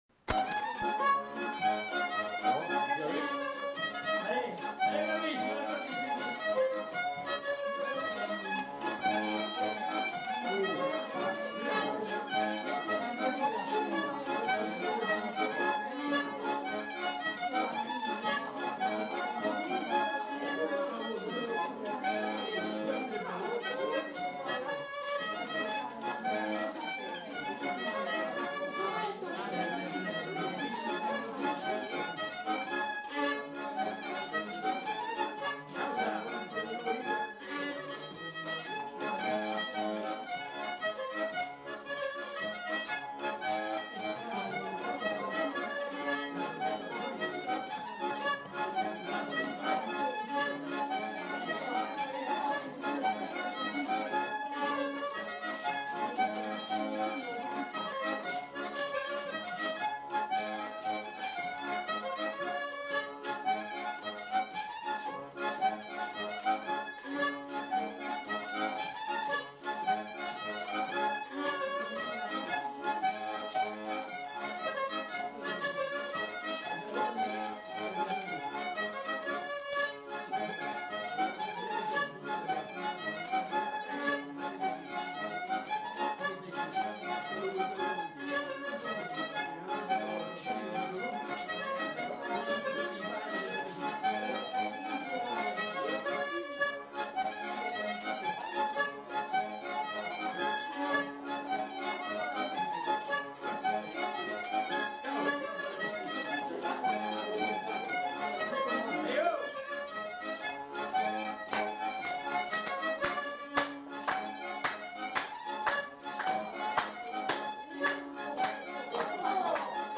Rondeau
Lieu : Allons
Genre : morceau instrumental
Instrument de musique : violon ; accordéon diatonique
Danse : rondeau